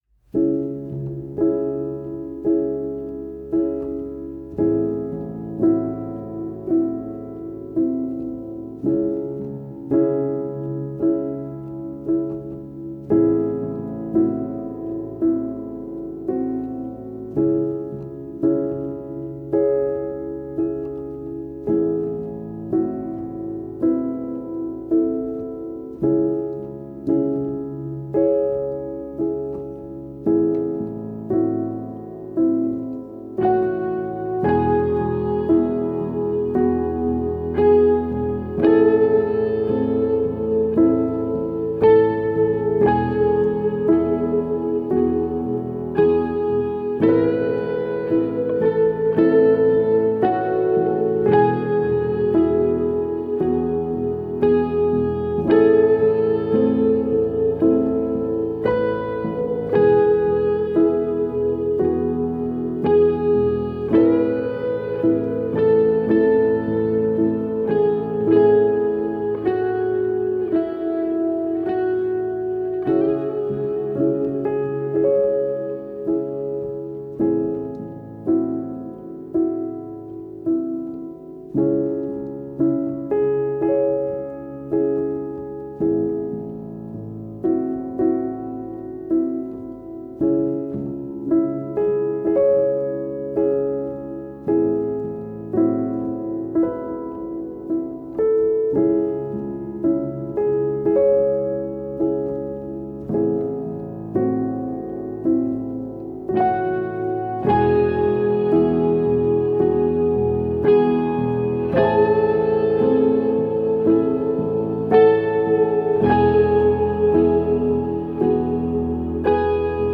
A soft, slow composition featuring felted piano and cello.
one with spacious guitar lead